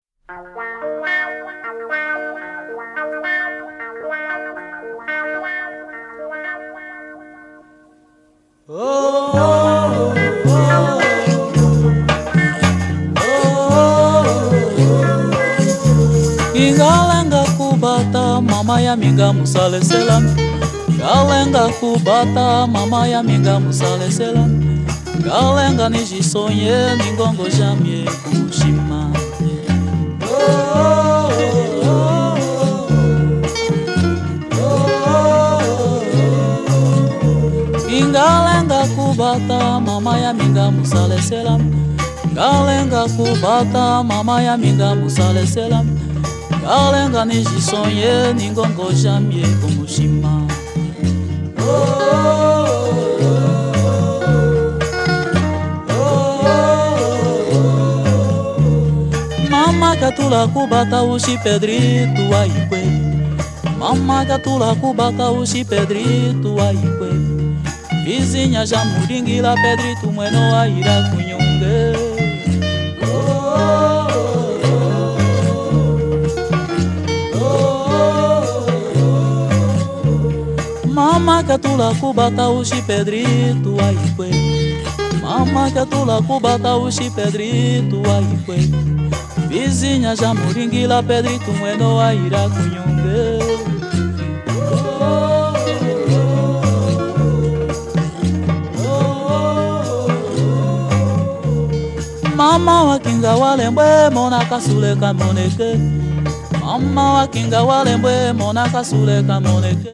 アフロビートの中にファド～ブラジル音楽の詩情！